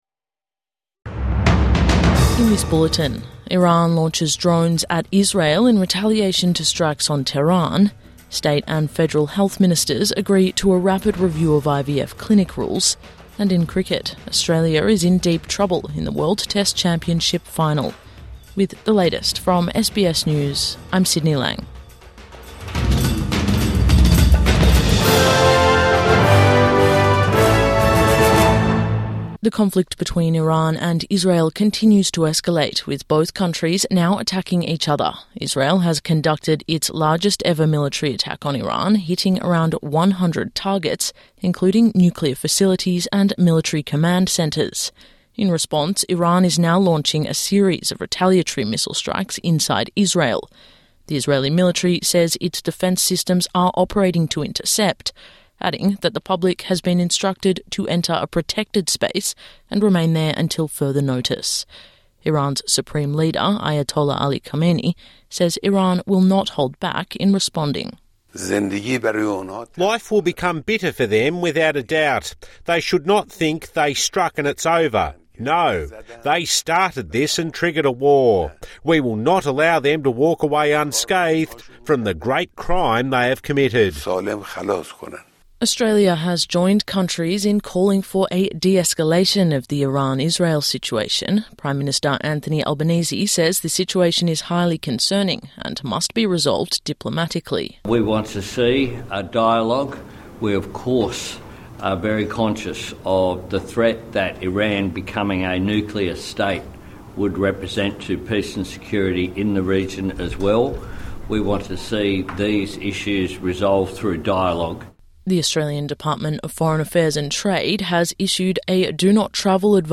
Iran retaliates against Israel | Morning News Bulletin 14 June 2025